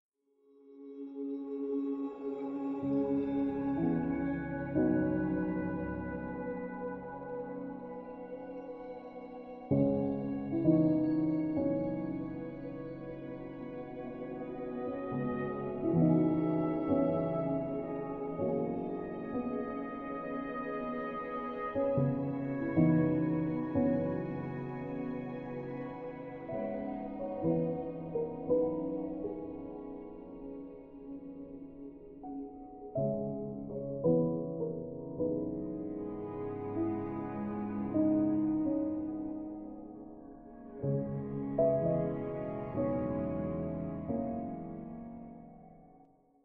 ambient-loop-piano.mp3